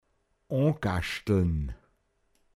pinzgauer mundart
o(n)gaschtln Garten bestellen, gartln
o(n)gaschlt den Garten bestellt;